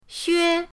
xue1.mp3